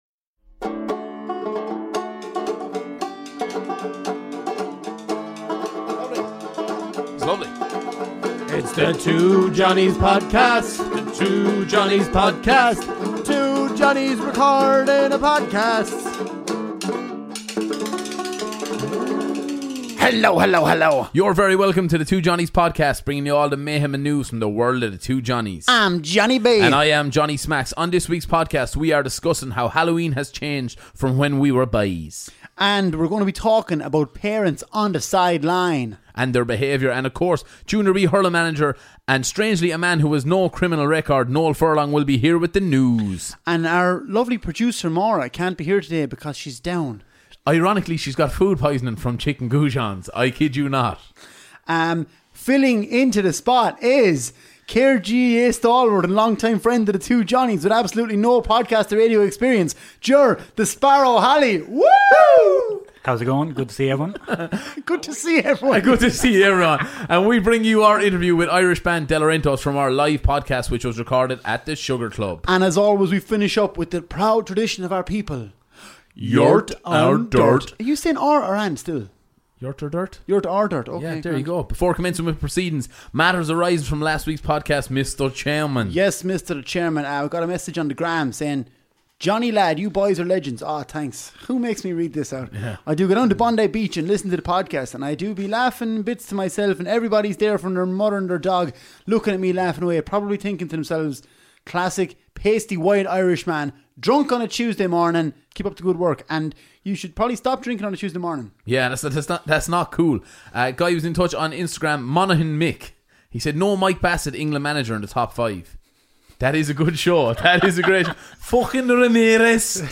Interview & performance from fantastic Irish band The Delarentos .